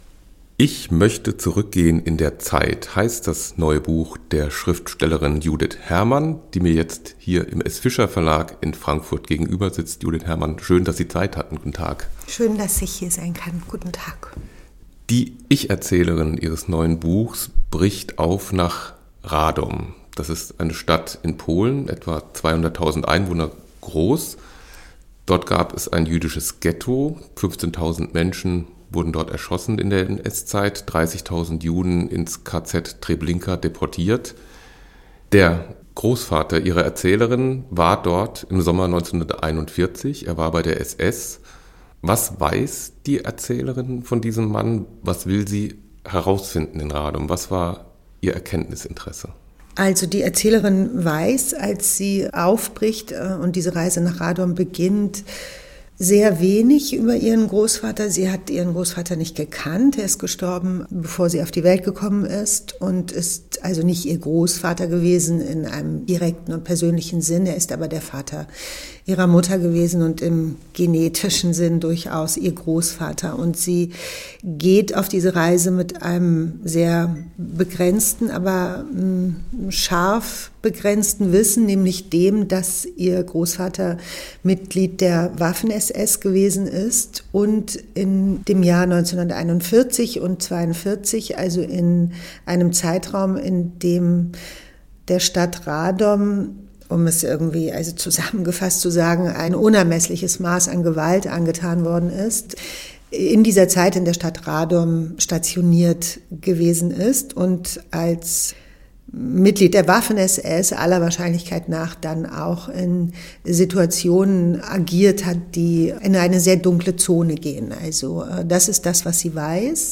Die Bestsellerautorin Judith Hermann im Gespräch